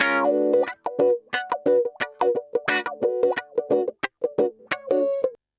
Index of /90_sSampleCDs/USB Soundscan vol.04 - Electric & Acoustic Guitar Loops [AKAI] 1CD/Partition C/05-089GROWAH